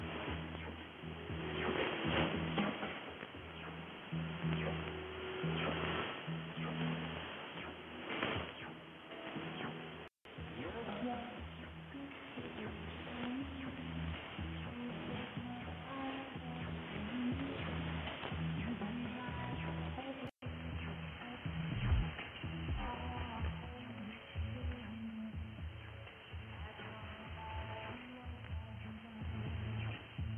Second 00-10 > JRC NRD 545 DSP
Second 10-20 > Icom IC-R8600
Second 20-30 > Winradio G33DDC Excalibur Pro
Radio Progreso is received in the same quality by all three radios using SAM and 6.4 kHz band width. The NRD 545's AGC, which its fast attack time, is clearly noticeable.